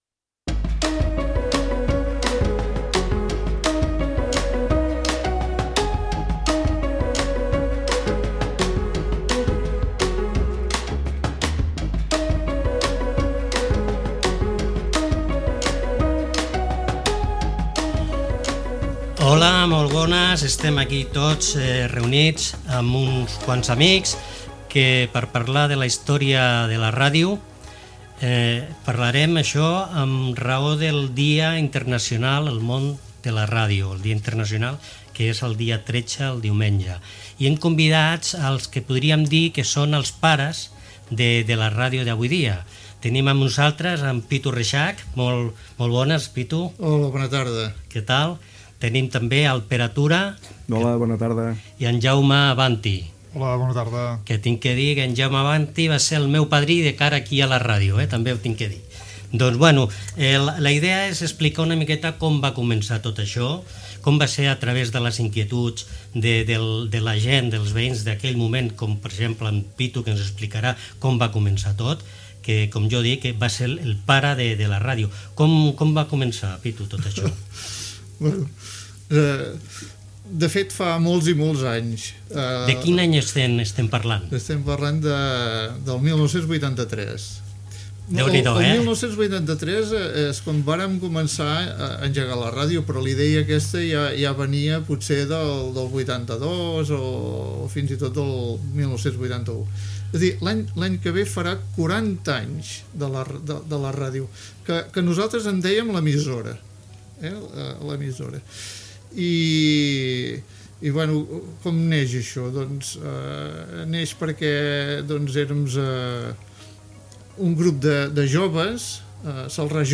Expliquen com es va crear l'emissora i diversos fets esdevinguts durant les dècades de 1980 i 1990. Gènere radiofònic Divulgació